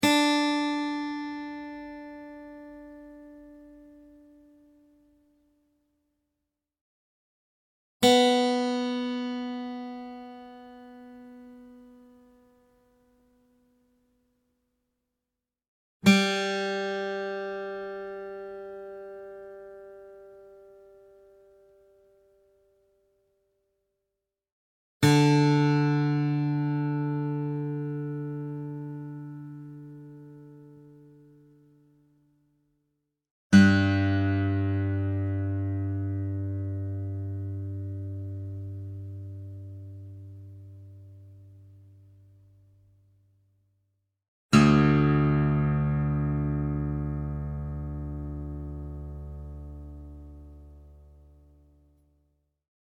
Real acoustic guitar sounds in Open G Tuning
Guitar Tuning Sounds